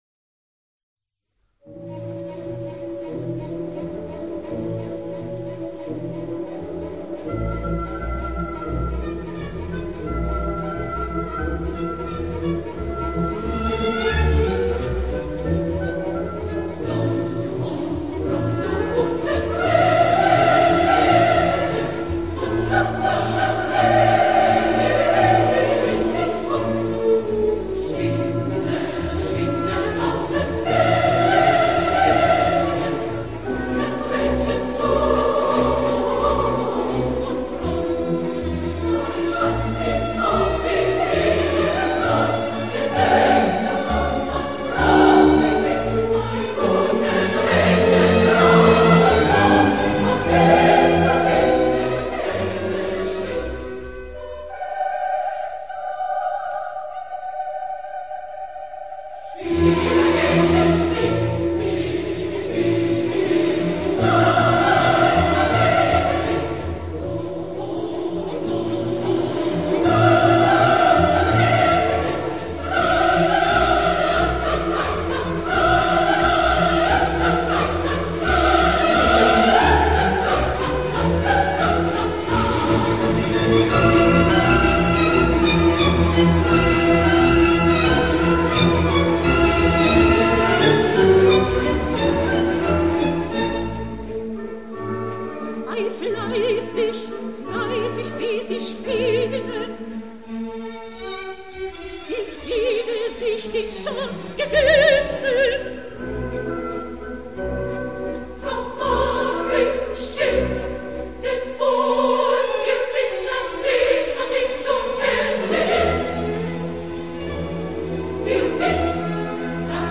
Les voix (2)
Choeur